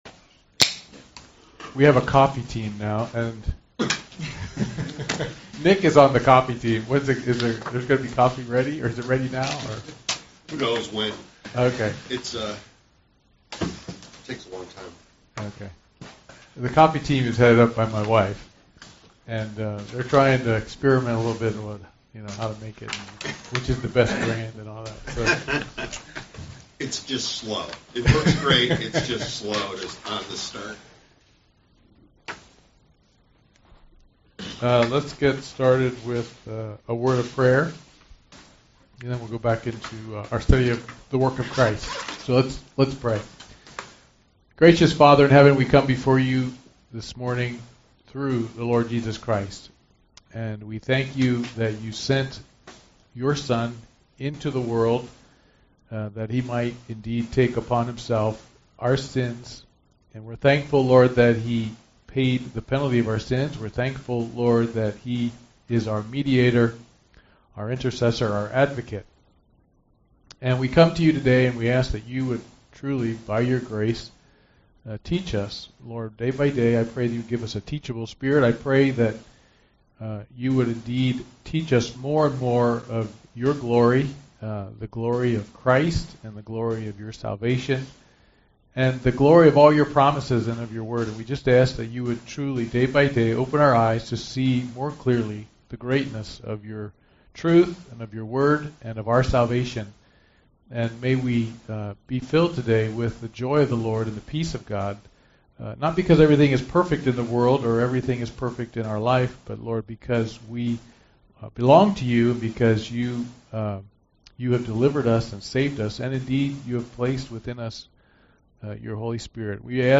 UN Service Type: Men's Bible Study « Sorrento